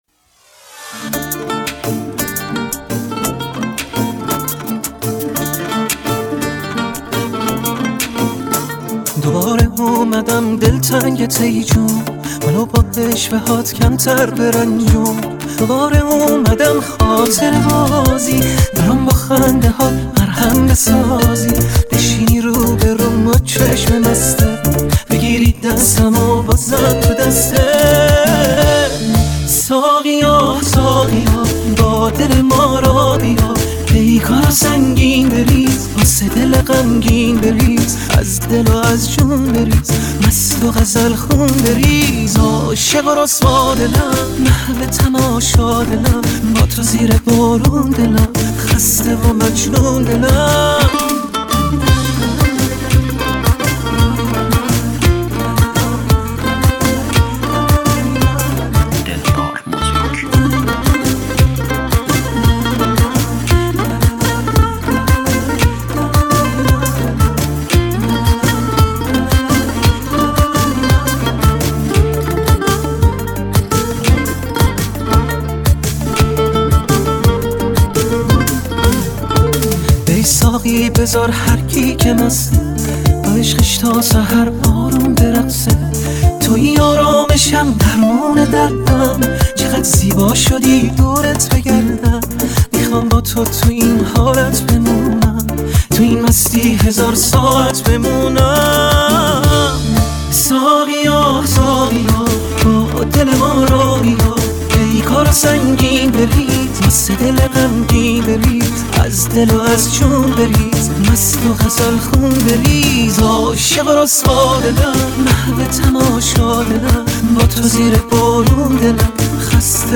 ریمیکس افزایش سرعت